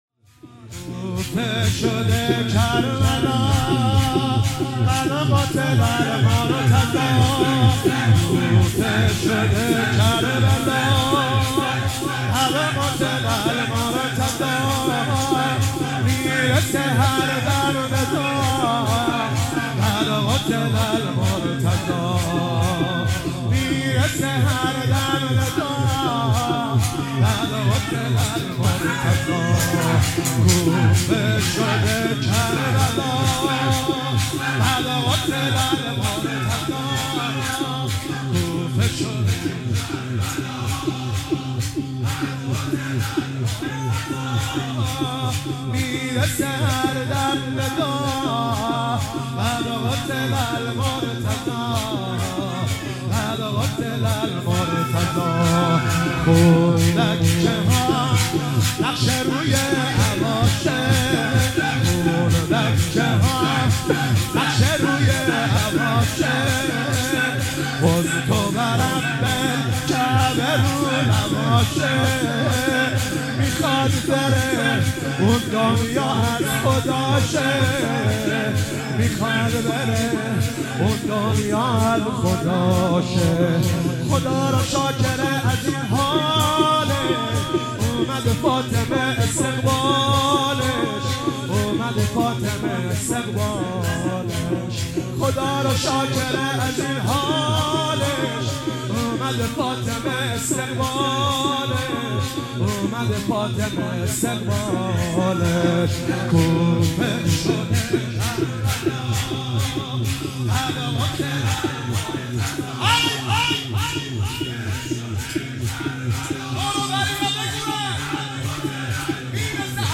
شور - کوفه شده کربلا قد قتل المرتضی